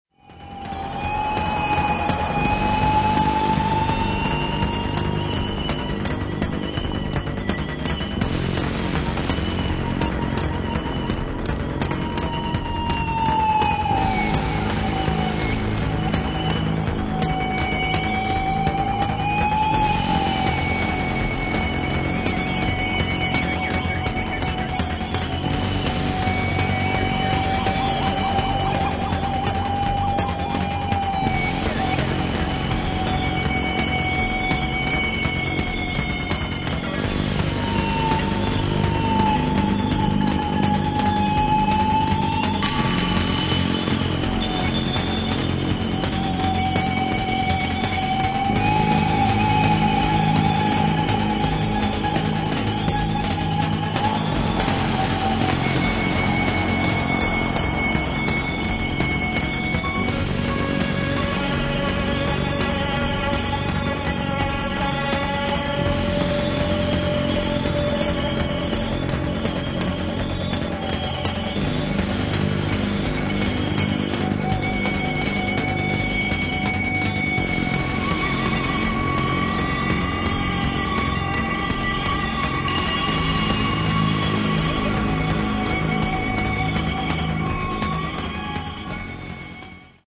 80'sエレクトロニックな感覚とシネマティックな雰囲気が随所に感じられるコンセプチュアルなコンピレーション作品